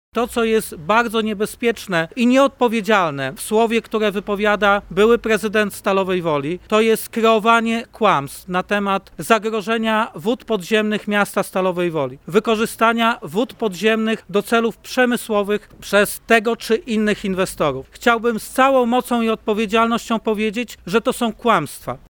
W Stalowej Woli w powstającym Strategicznym Parku Inwestycyjnym trwa budowa fabryki folii miedzianej realizowana przez koreańskiego inwestora. W tej sprawie konferencję prasową zwołał radny sejmiku wojewódzkiego i były prezydent Stalowej Woli Andrzej Szlęzak, który poddał w wątpliwość, czy wszystko odbyło się zgodnie z obowiązującymi procedurami, a inwestycja może zagrażać środowisku i zdrowiu mieszkańców.